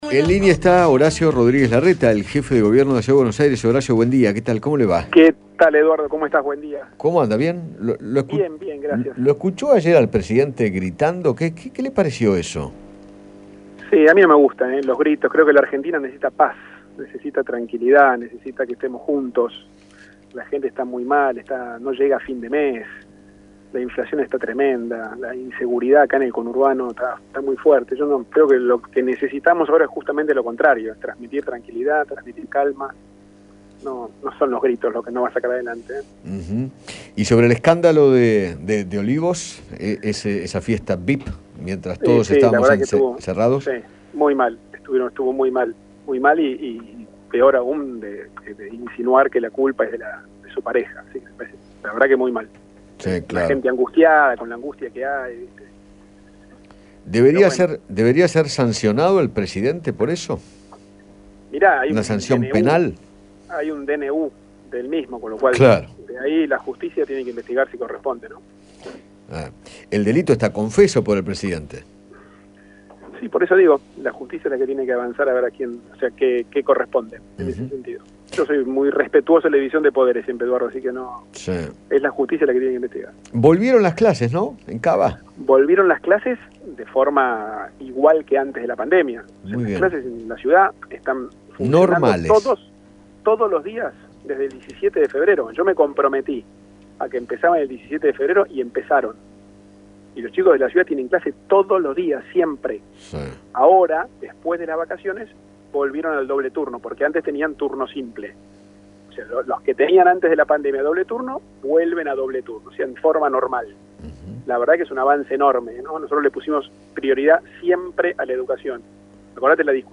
Horacio Rodríguez Larreta, jefe de Gobierno de la Ciudad de Buenos Aires, conversó con Eduardo Feinmann sobre el último discurso que dio Alberto Fernández a los gritos y expresó que “necesitamos transmitir lo contrario, paz y calma”. Además, opinó acerca del escándalo en Olivos, el regreso a clases, y la campaña de vacunación.